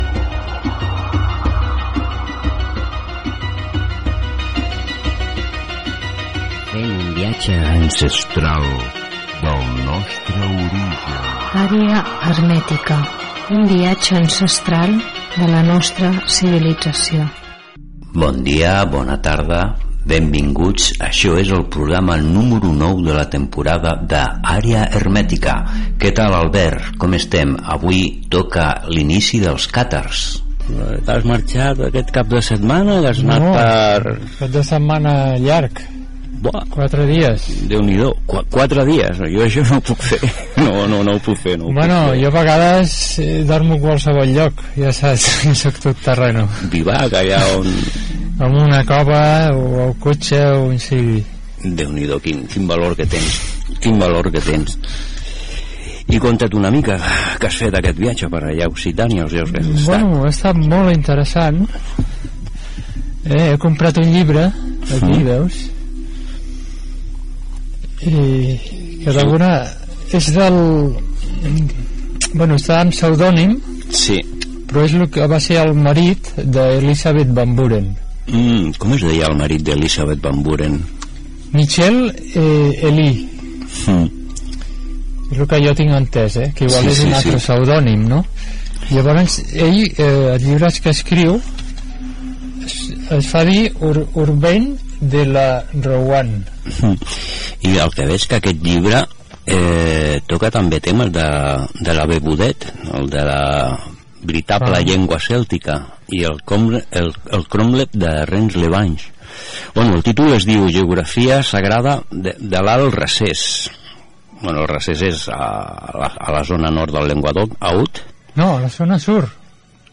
Careta del programa, espai dedicat a l'inici dels càtars
Divulgació
FM